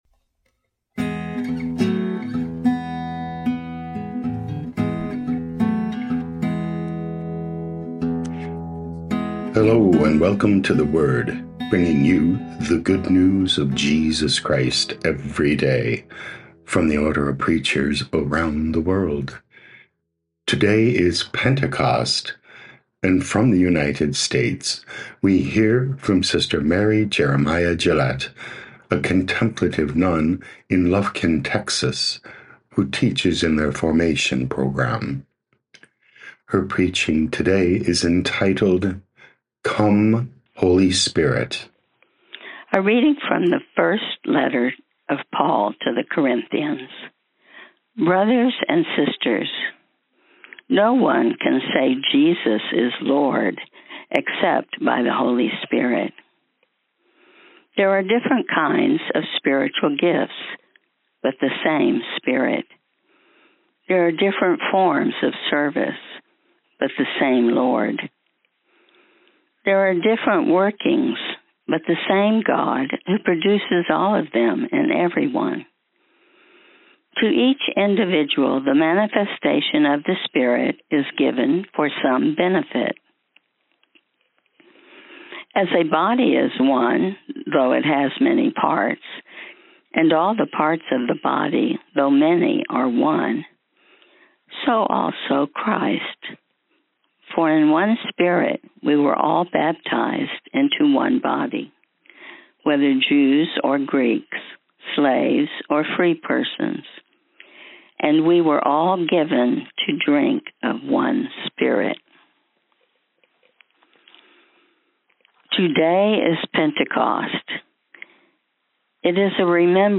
daily homilies from the Order of Preachers